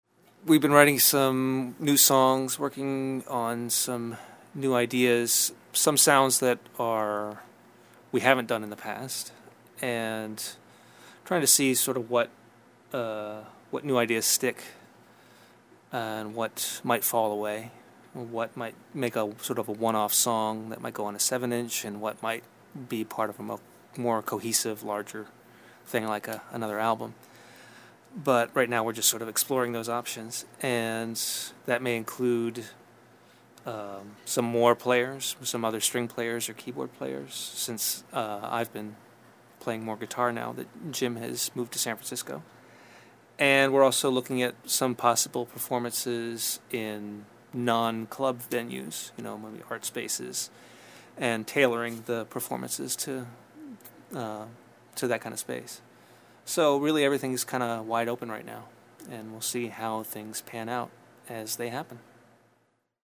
THE ORCHID INTERVIEW – September 2012